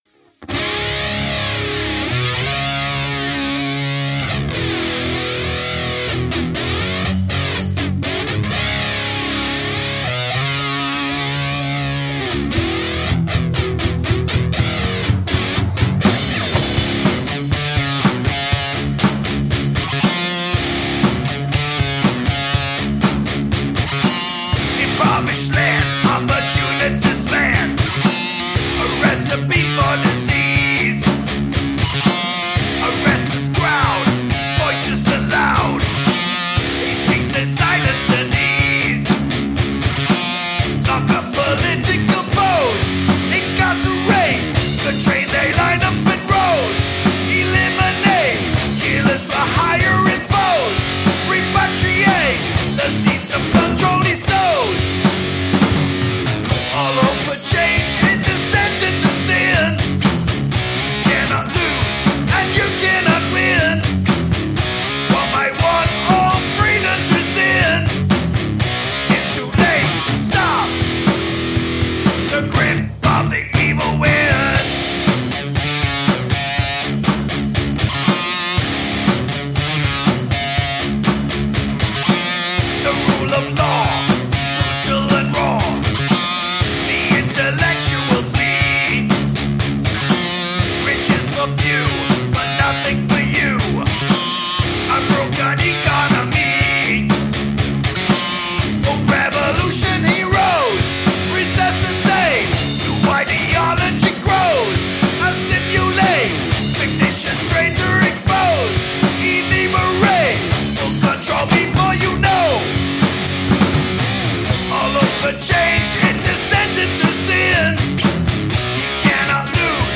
Please critique work in progress - Alternative Rock Composition
Our band went to a studio and got poor results, so we decided to record on our own. Here is our first preliminary mix - just singing, rhythm guitar and drums.